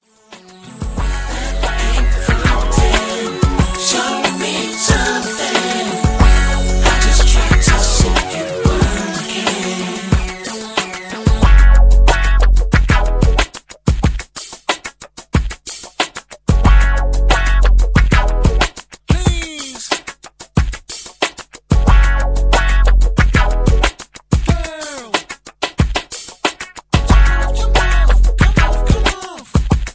Just Plain & Simply "GREAT MUSIC" (No Lyrics).